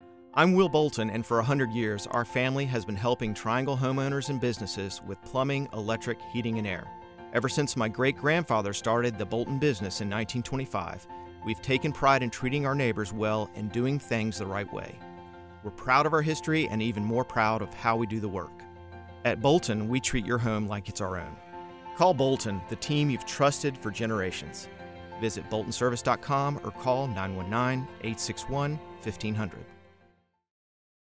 As Heard on Capitol Broadcasting